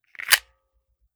9mm Micro Pistol - Loading Magazine 002.wav